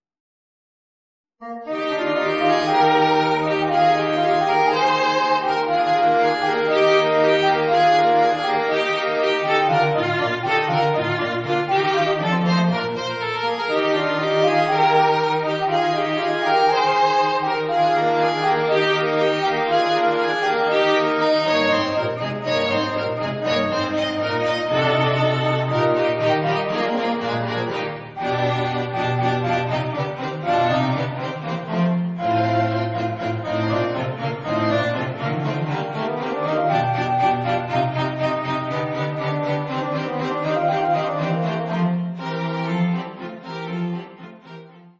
bassoon, violin, viola, violoncello
3. Allegro
(Audio generated by Sibelius)